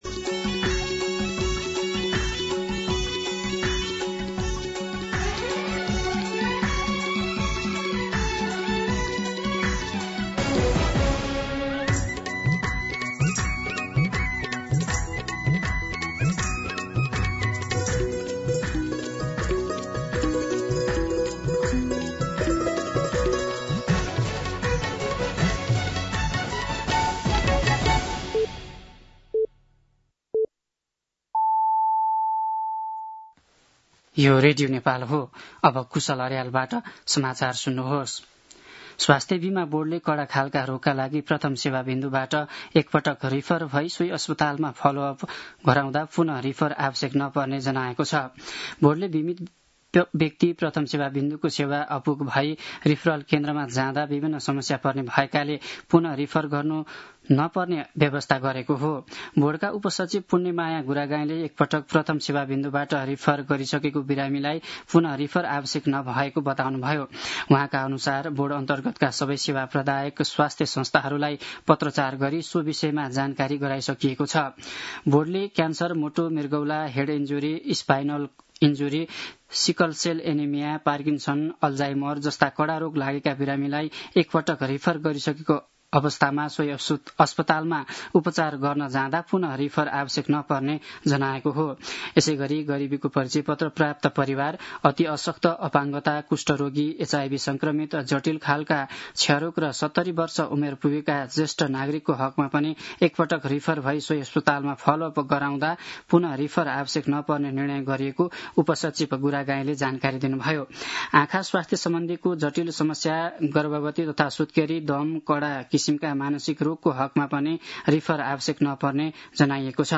दिउँसो ४ बजेको नेपाली समाचार : २८ असोज , २०८२
4pm-News-06-28.mp3